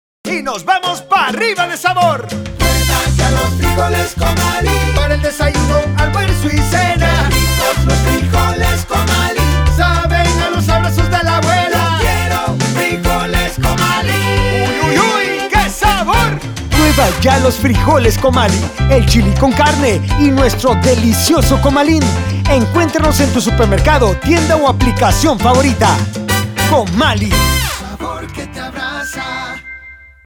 Cuña Frijoles Comali
COMALI-FRIJOLES-RADIO-30ss-Audiologo-Metrica.mp3